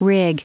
Prononciation du mot rig en anglais (fichier audio)
Prononciation du mot : rig